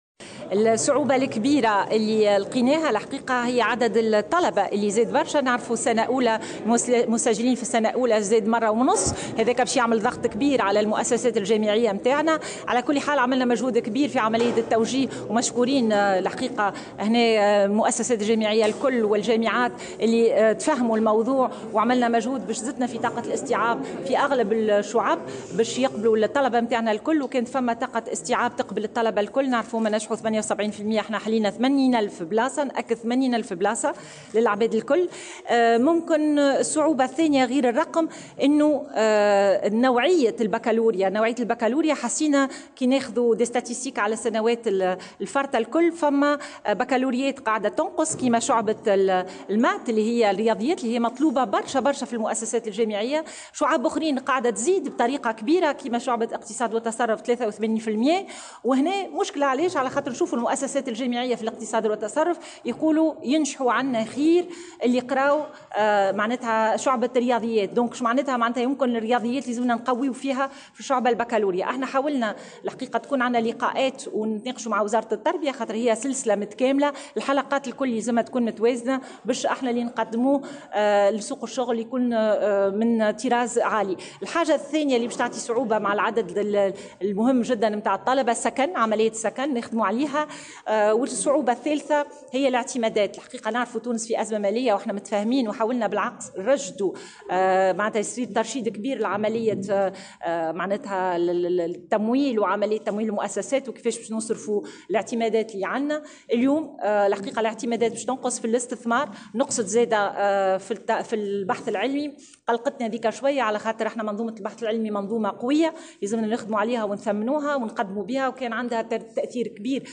تحدثت وزيرة التعليم العالي و البحث العلمي، ألفة بن عودة، خلال ندوة صحفية عقدتها اليوم الاثنين، عن أهم الاستعدادات للعودة الجامعية.